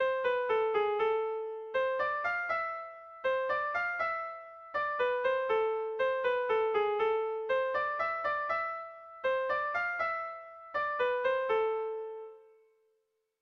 Sehaskakoa
ABAB